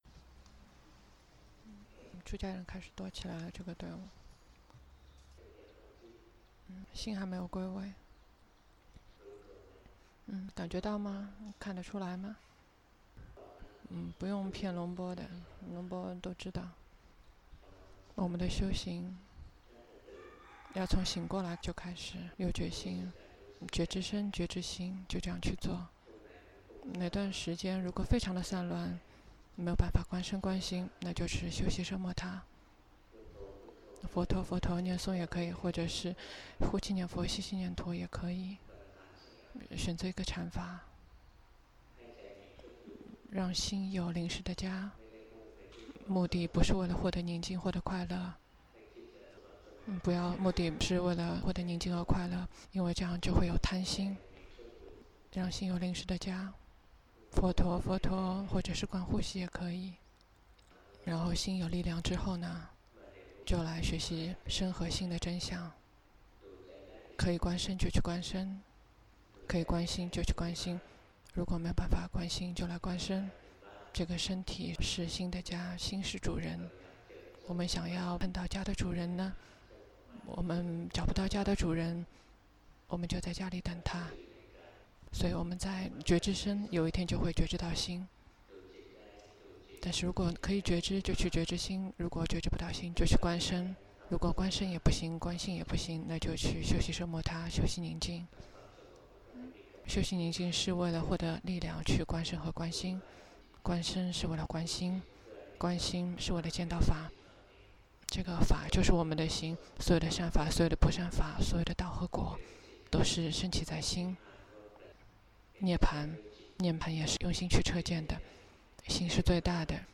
長篇法談｜心無法指揮但可訓練——隆波帕默尊者 - 靜慮林